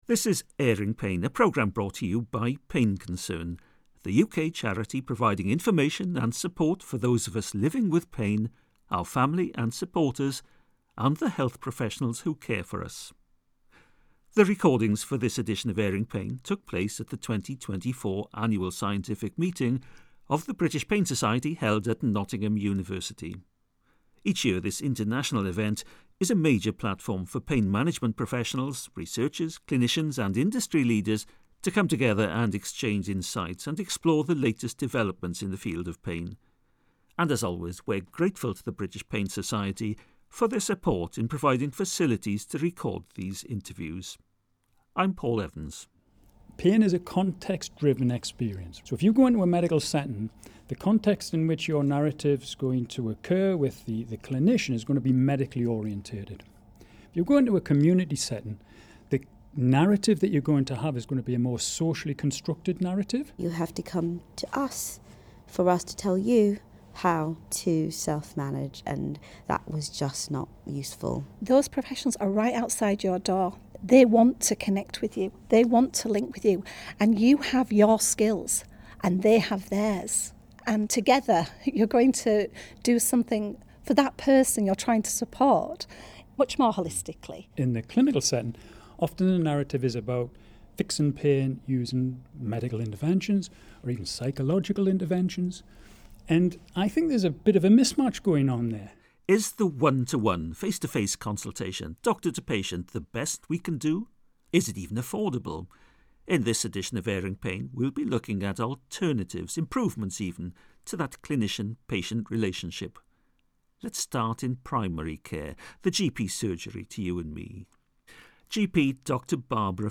The interviews were recorded at the British Pain Society’s Annual Scientific Meeting, 2024.